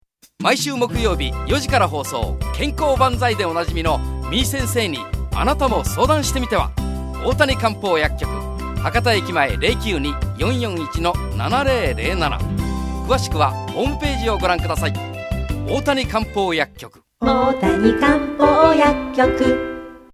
☆ラジオＣＭ配信☆
さぁ〜皆様、歌ってみましょう♪